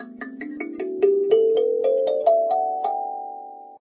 kalimba_scale.ogg